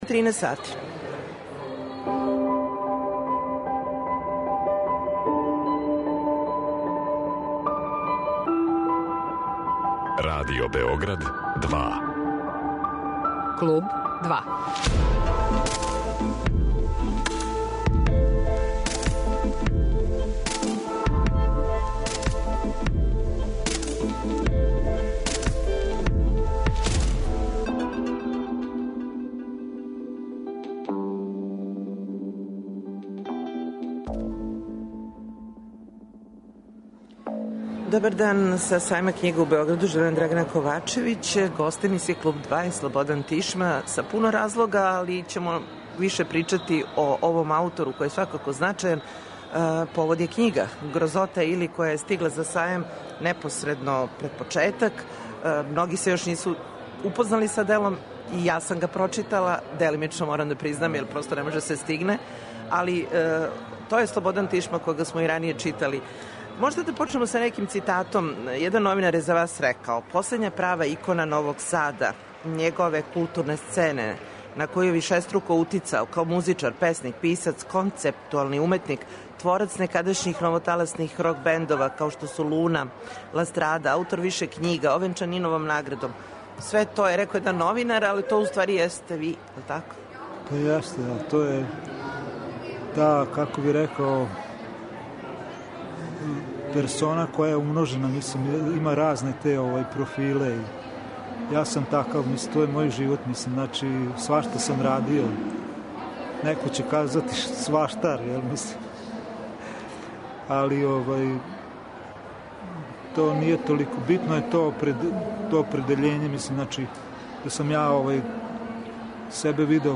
Гост у нашем студију на Сајму књига је Слободан Тишма .